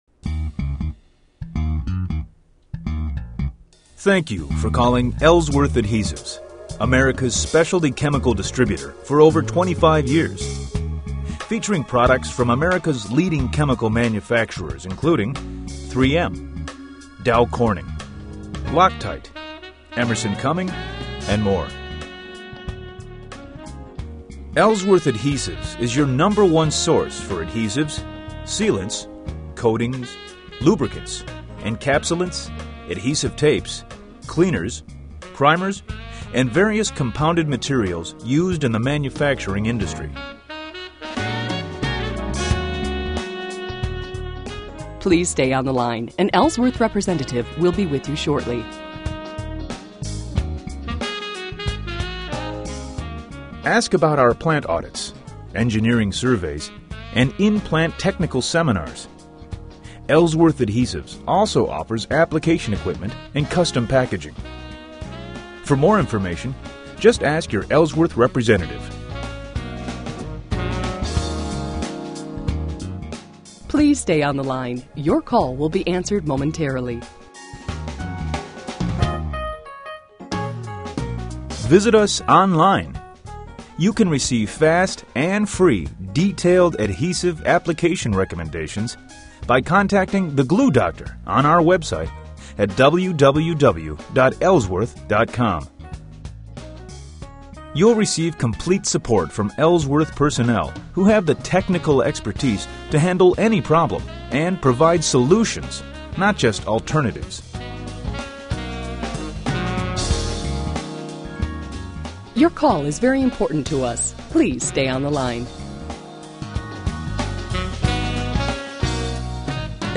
Ellsworth Adhesives On-Hold Messaging